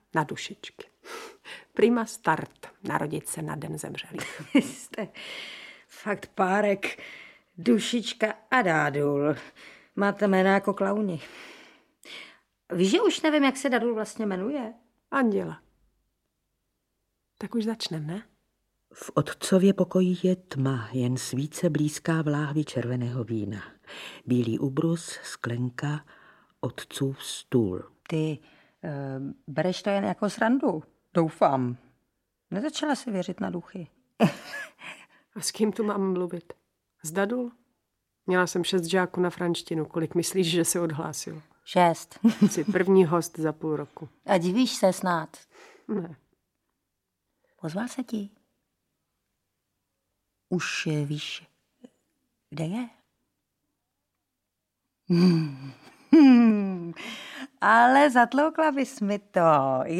Audiobook
Read: Taťjana Medvecká